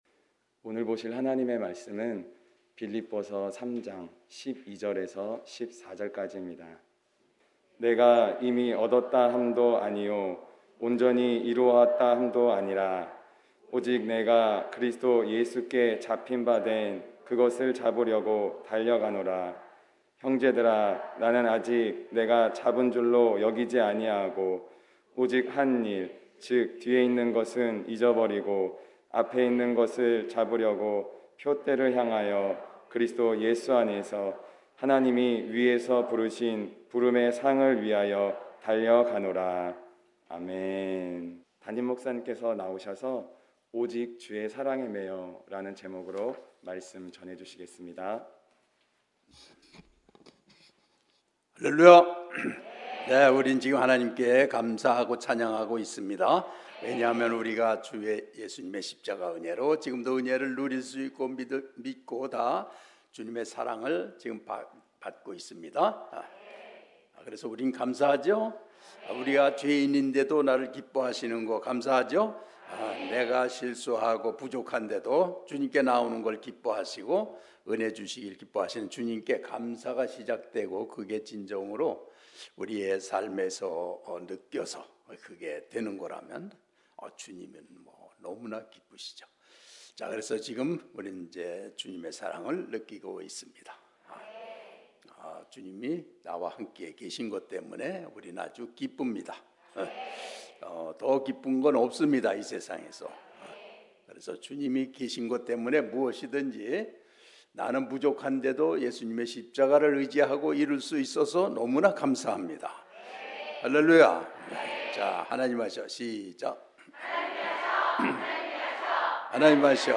주일9시예배